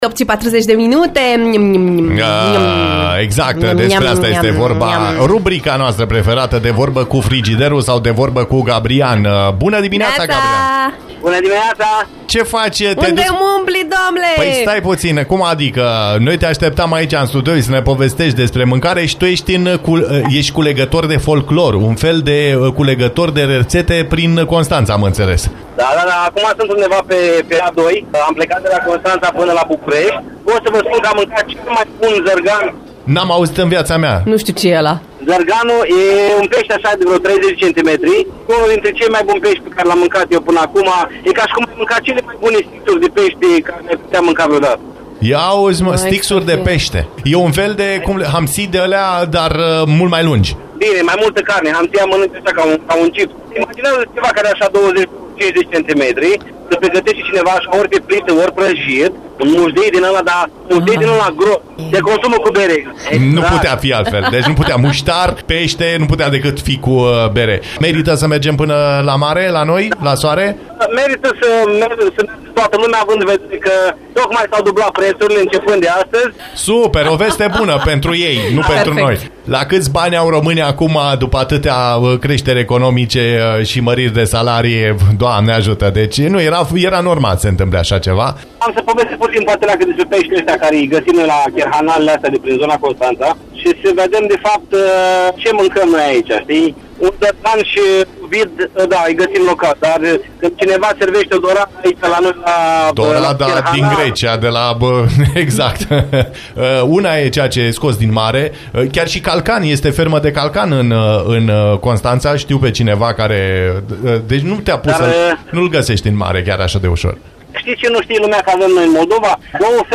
în direct de pe litoral